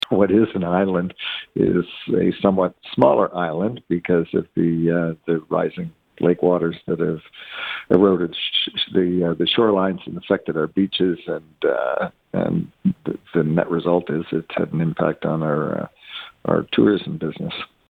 Mayor Steve Ferguson tells Quinte News this is the “most pressing concern” he will raise at the Association of Municipalities of Ontario conference in Ottawa next week.